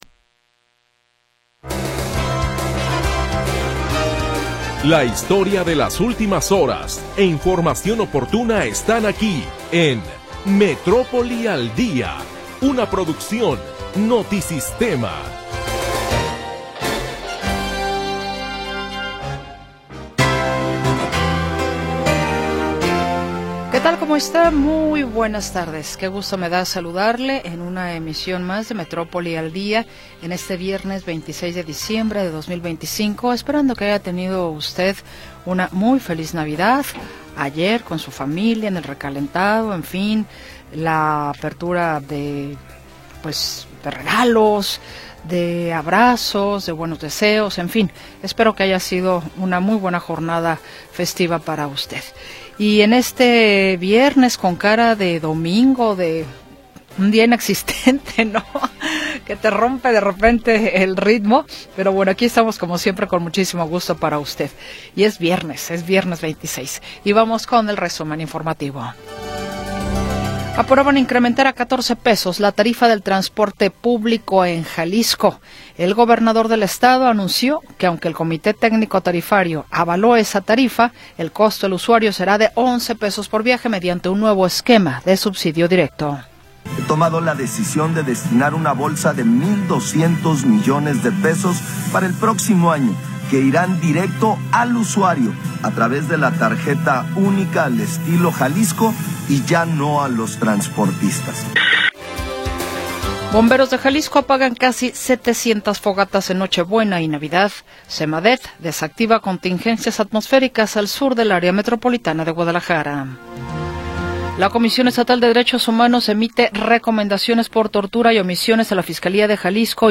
Análisis, comentarios y entrevistas